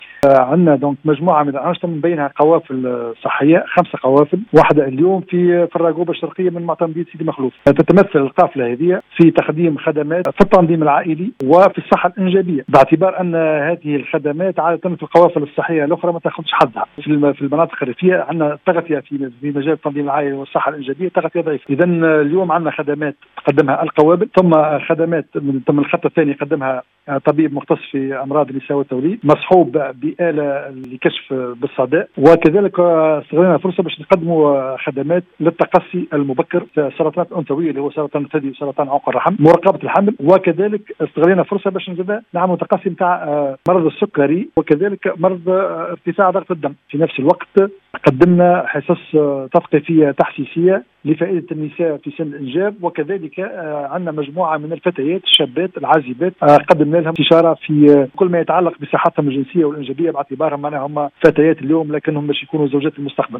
المندوب الجهوي للاسرة و العمران البشري بمدنين لزهر النمري (تسجيل)